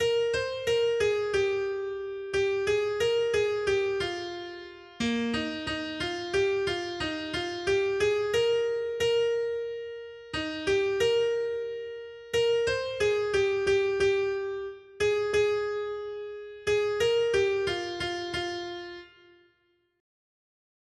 Noty Štítky, zpěvníky ol255.pdf responsoriální žalm Žaltář (Olejník) 255 Skrýt akordy R: Blahoslavená jsi, Panno Maria, žes nosila Syna věčného Otce. 1.